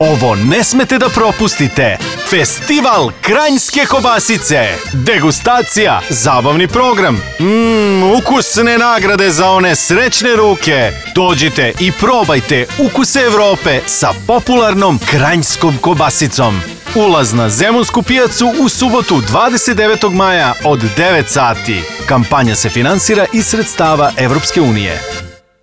RA-oglas_Festival-kranjske-kobasice.wav